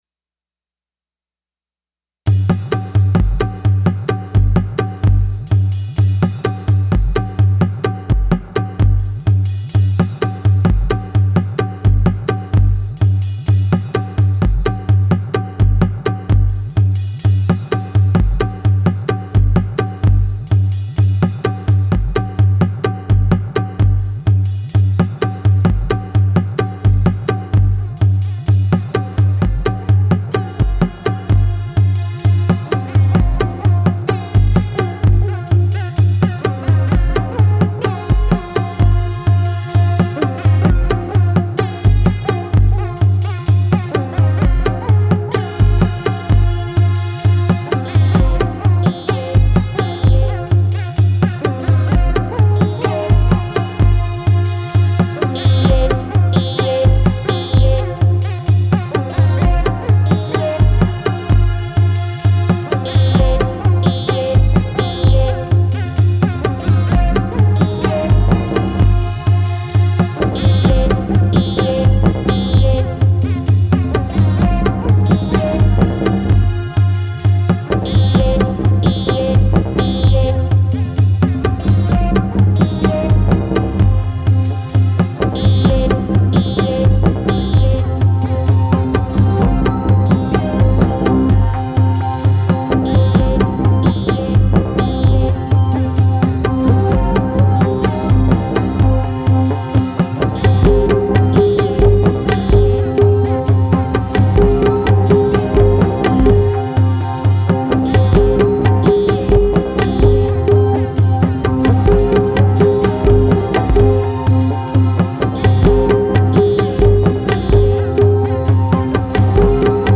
readings beneath the poet tree ep5